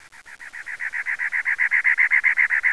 bird2.wav